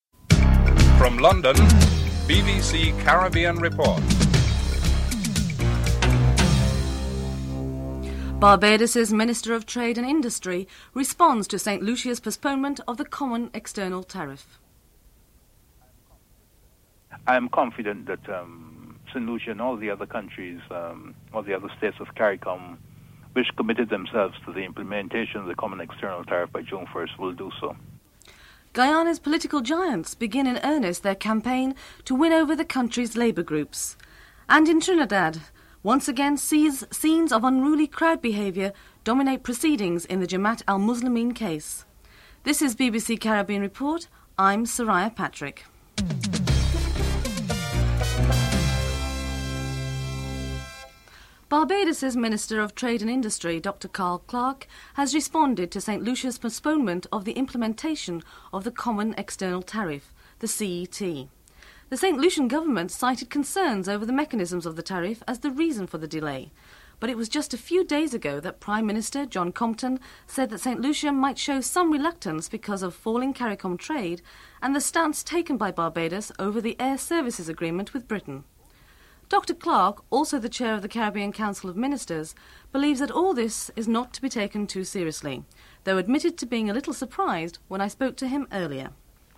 2. Dr. Carl Clarke, Barbados Minister of Trade and Chair of the Caribbean Council of Ministers notes his surprise that St. Lucia is postponing the adoption of the CET.